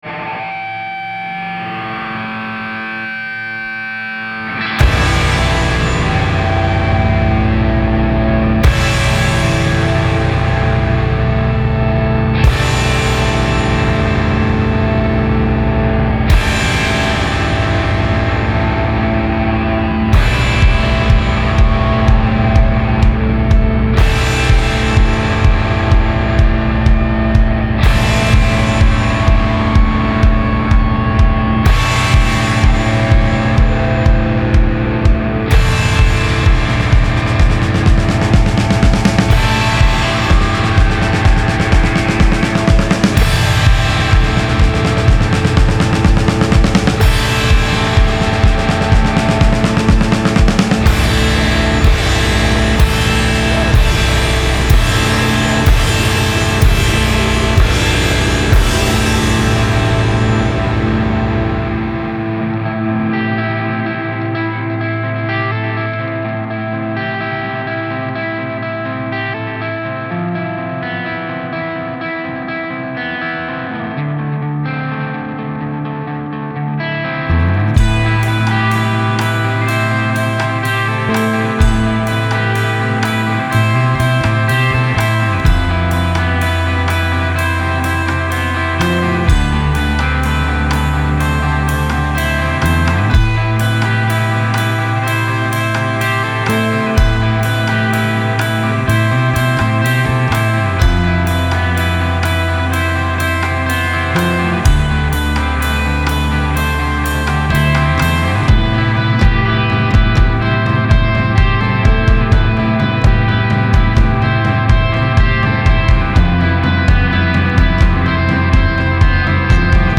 Trio espagnol originaire de Cordoba